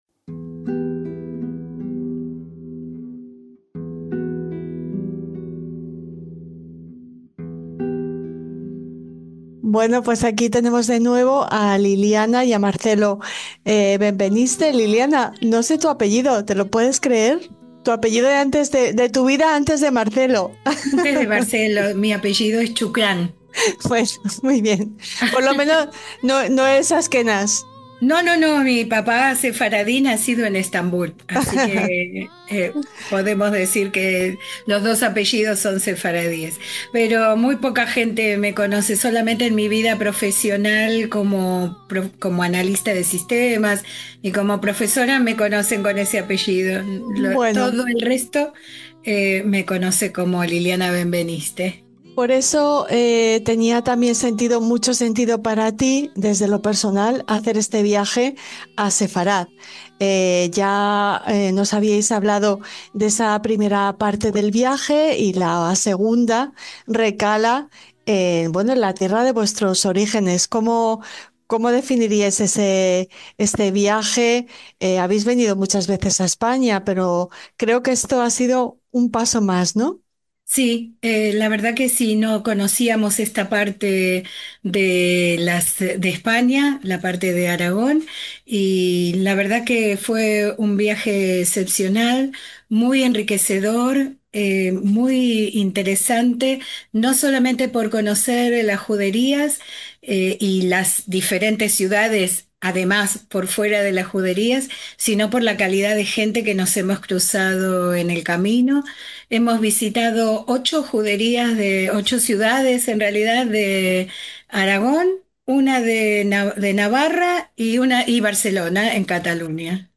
LA ENTREVISTA.